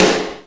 normal-hitclap.ogg